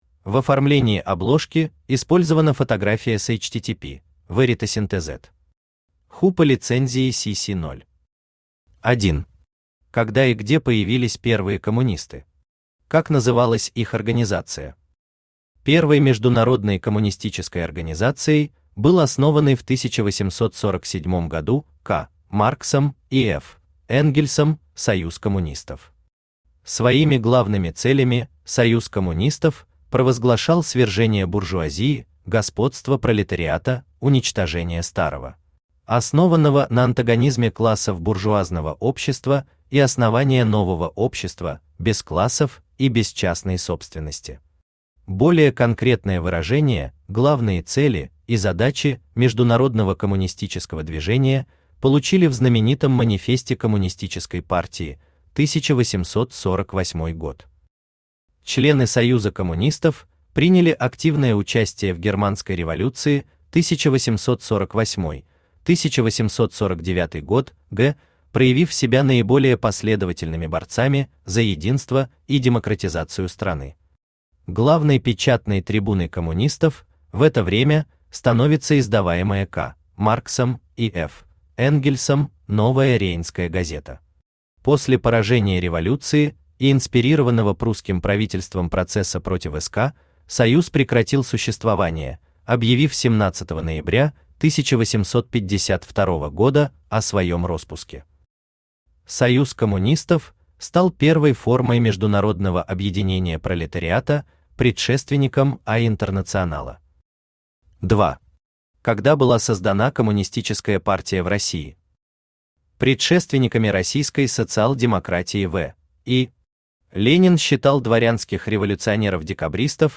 Аудиокнига Коммунизм как политика | Библиотека аудиокниг
Aудиокнига Коммунизм как политика Автор Даниил Андреевич Ушаков Читает аудиокнигу Авточтец ЛитРес.